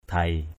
/d̪ʱeɪ/ (d.) trán = front.